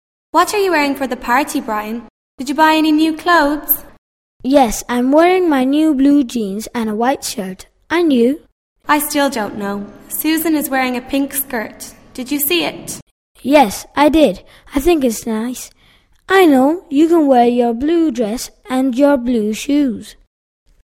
Descripci�n: El video representa la conversaci�n entre varias personas (protagonistas que aparecen y texto asociado se muestra m�s abajo).